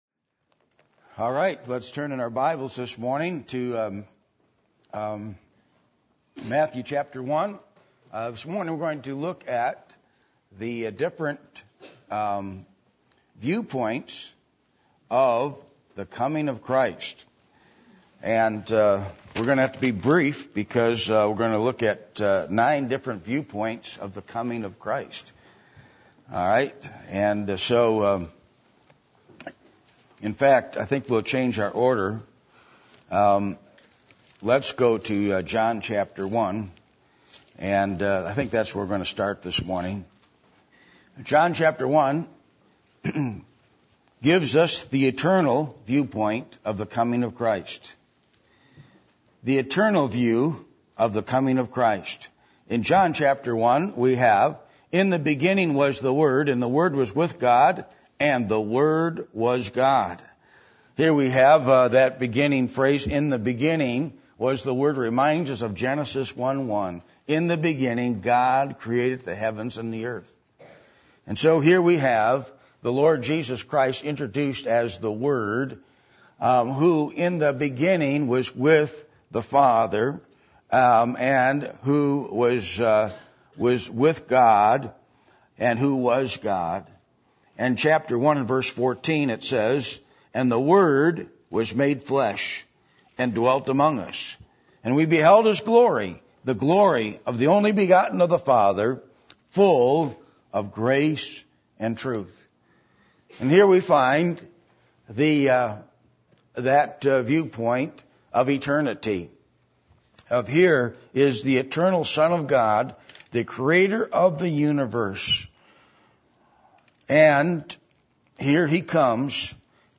Passage: Matthew 1:18-25 Service Type: Sunday Morning